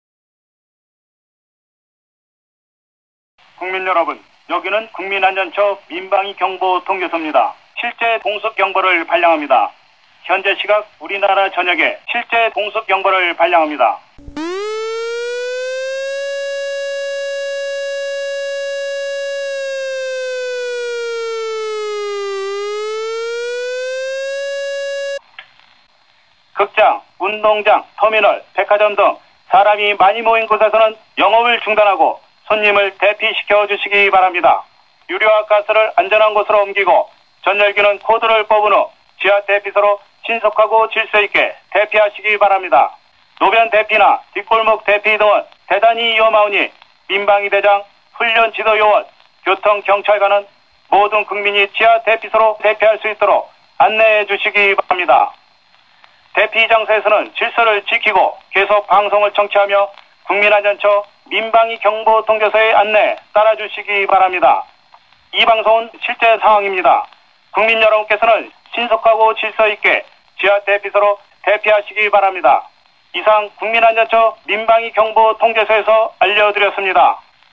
airraid.mp3